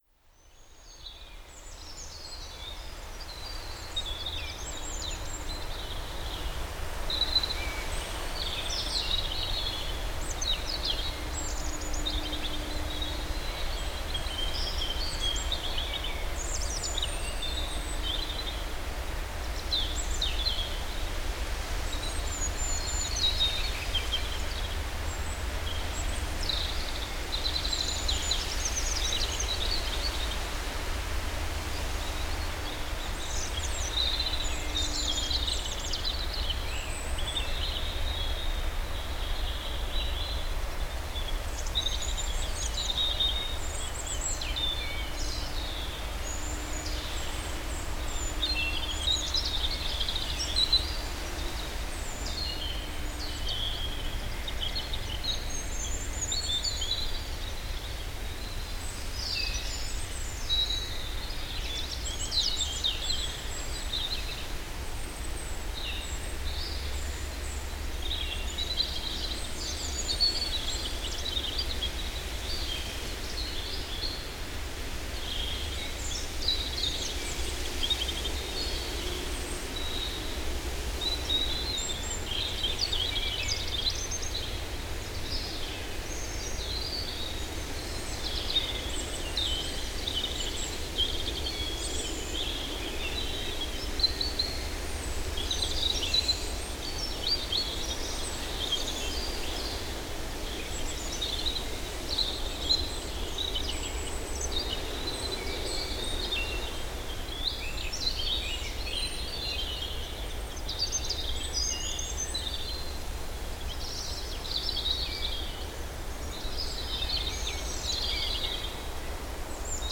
Dans la forêt: Sons de la nature pour dormir, relaxation, travailler, se concentrer
Le soleil joue dans les cimes vertes, qui bruissent doucement dans la brise. Les oiseaux gazouillent et, au loin, vous entendez le murmure d´un calme ruisseau. Vous êtes dans une forêt luxuriante et sereine, seul avec la musique de la nature.
"Dans la forêt" est une série de paysages sonores plaisants et variés, que vous pouvez écouter lorsque vous souhaitez vous détendre, vous endormir, ou pour vous concentrer au travail.